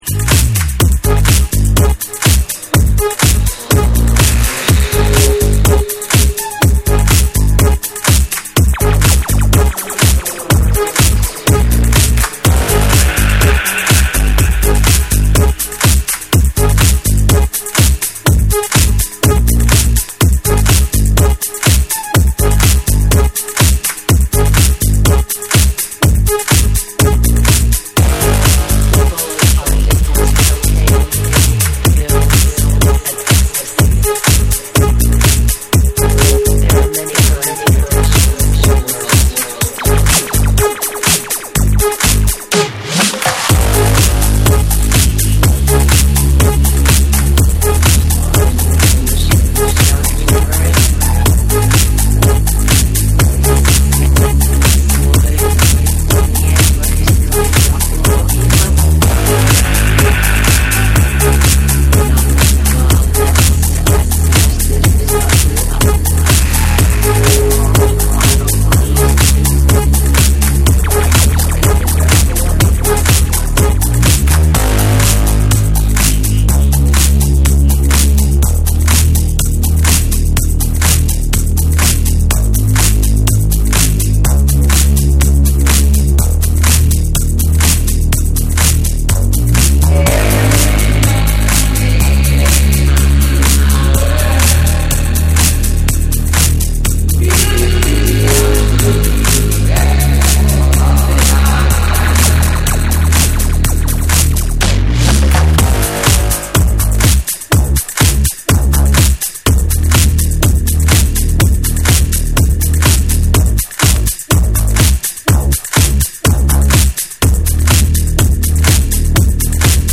グルーヴィーなベースラインとディープなシンセ が特徴のテックハウスを披露する1。
TECHNO & HOUSE